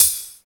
45 HAT.wav